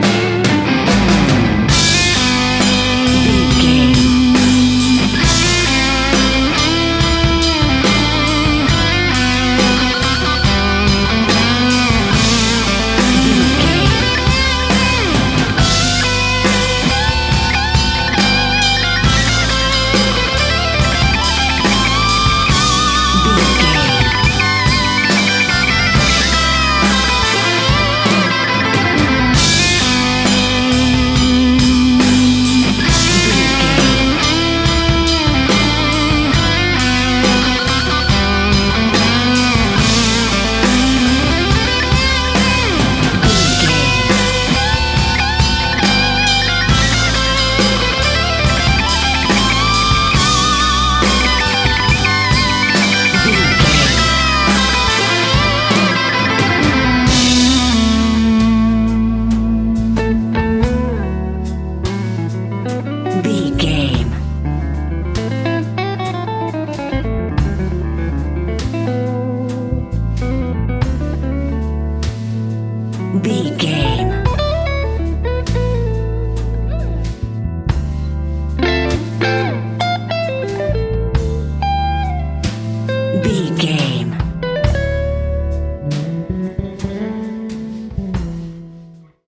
Phrygian
Slow
drums
electric guitar
bass guitar
Sports Rock
hard rock
lead guitar
aggressive
energetic
intense
nu metal
alternative metal